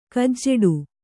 ♪ kajjeḍu